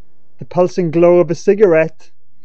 HAS-Corpus / Audio_Dataset /fear_emotion /1475_FEA.wav